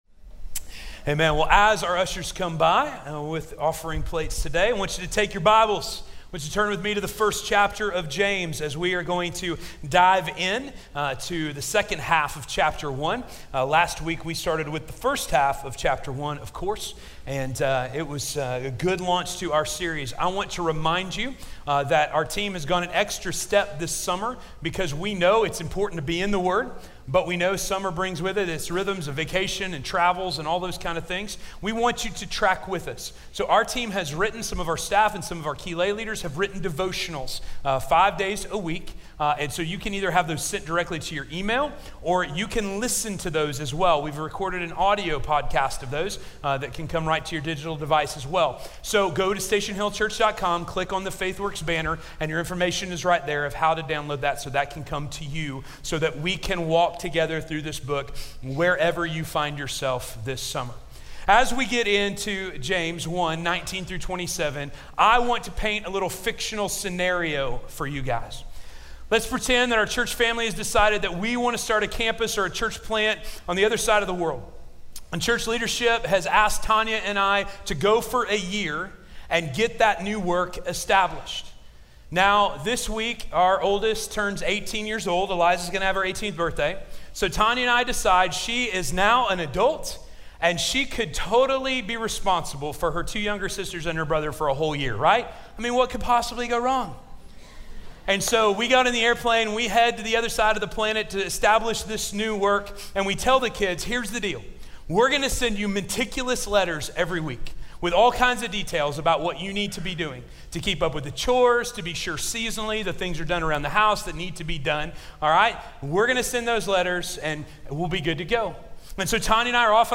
Faith Does - Sermon - Station Hill